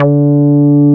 P MOOG D4MP.wav